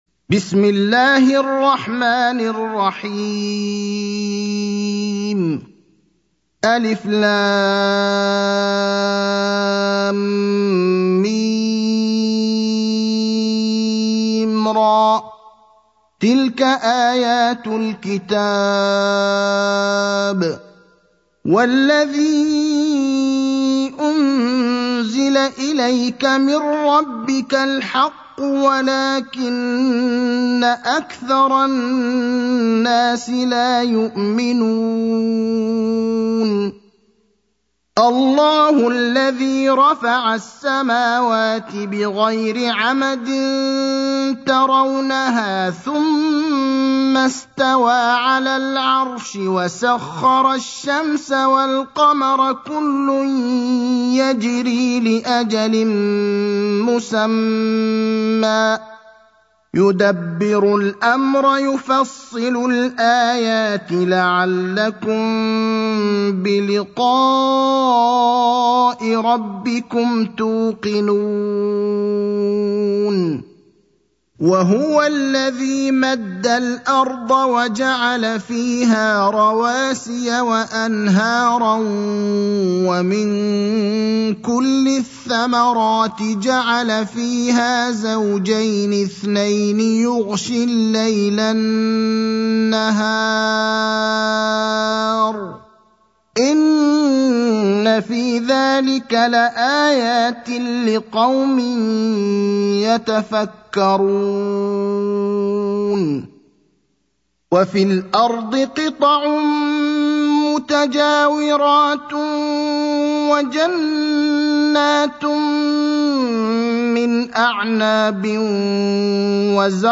المكان: المسجد النبوي الشيخ: فضيلة الشيخ إبراهيم الأخضر فضيلة الشيخ إبراهيم الأخضر الرعد (13)